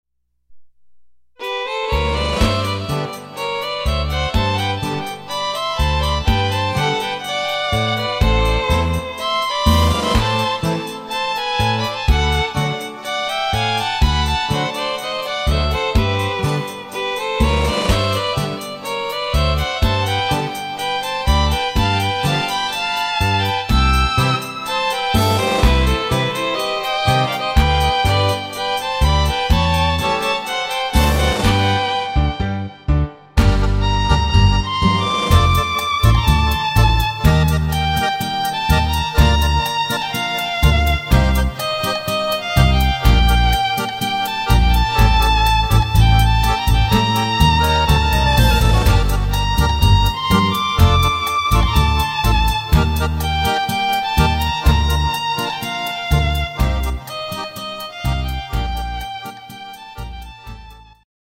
echter Argentinischer Tango